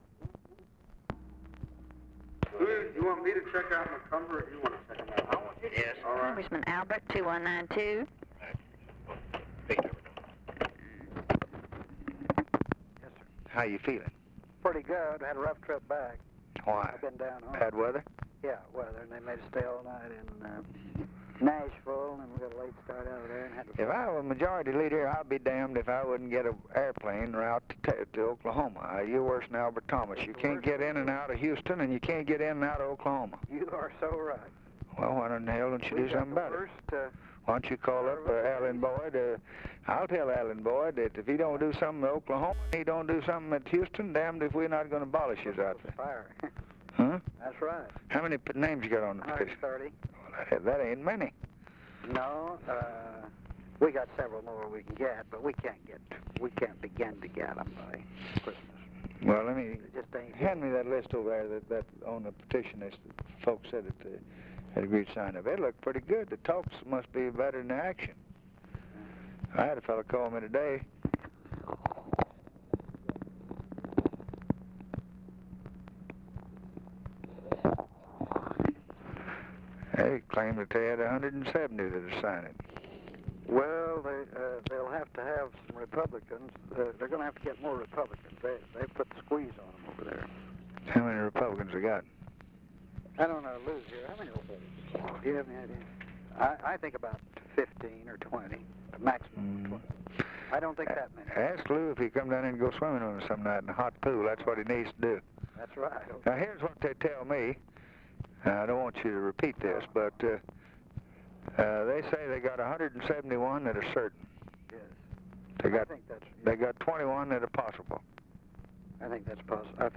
Conversation with CARL ALBERT, December 10, 1963
Secret White House Tapes